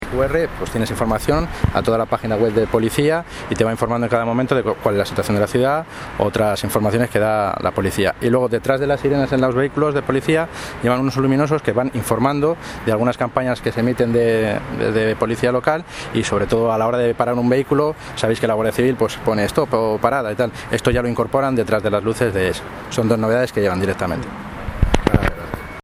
Audio - Roberto Sanchez (Concejal de Seguridad Ciudadana) sobre nuevos coches policía